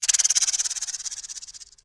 Snake Rattle Single Shot
Animals
Snake Rattle Single Shot.mp3